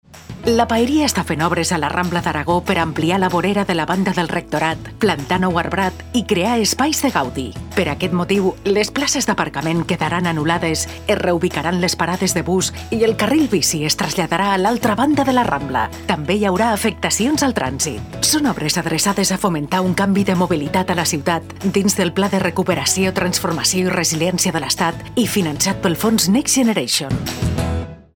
Segona falca radiofònica: